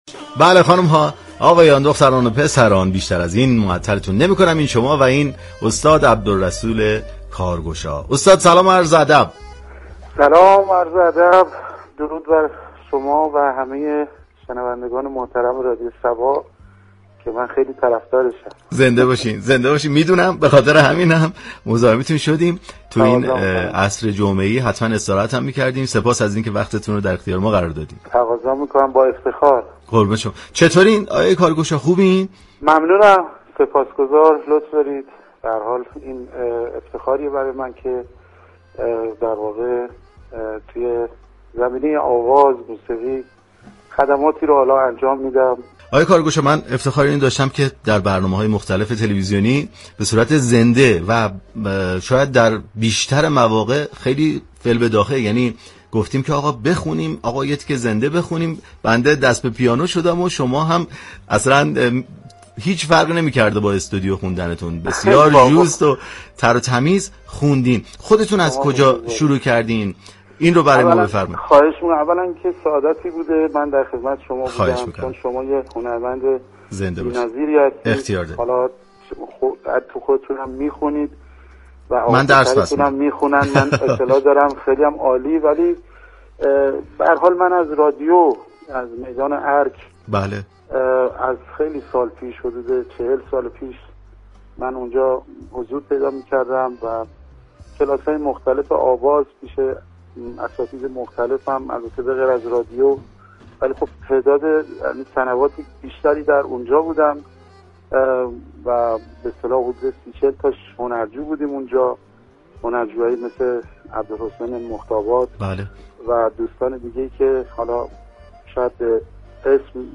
برنامه موسیقی محور